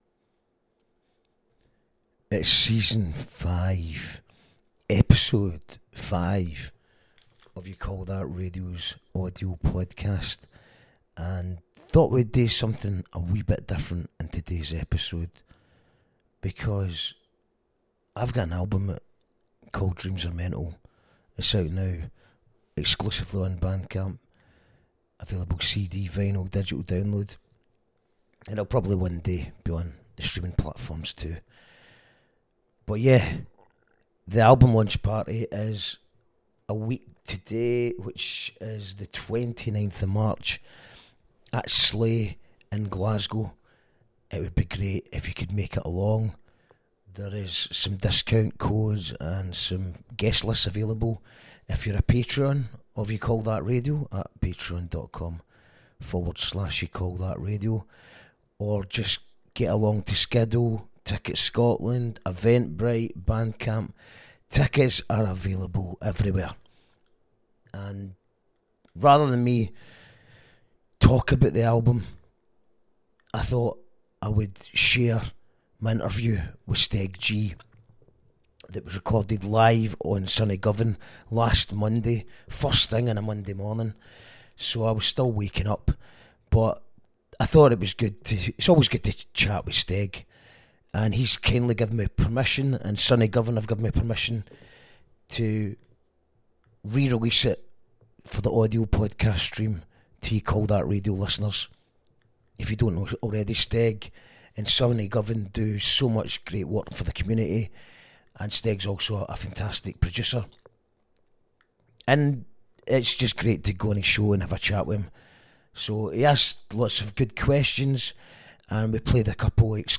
(Live on Sunny G)